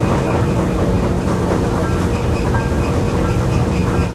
techage_oildrill.ogg